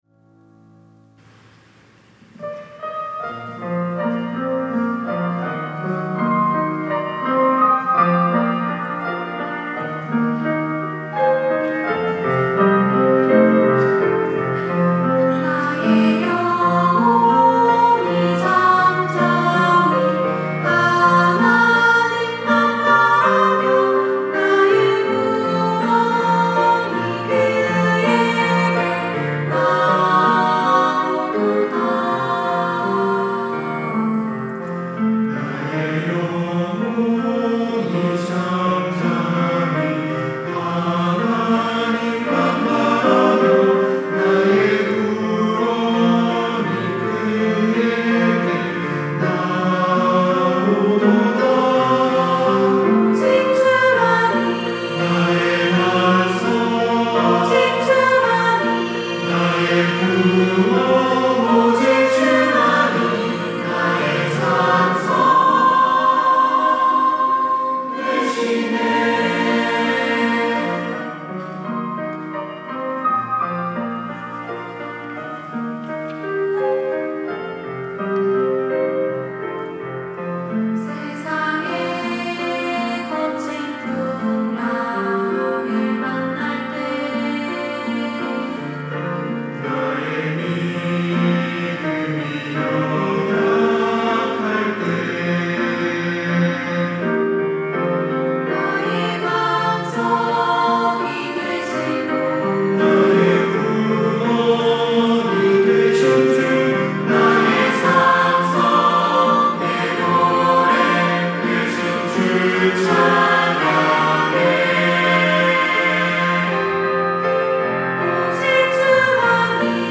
특송과 특주 - 시편 62편
청년부 일일 찬양대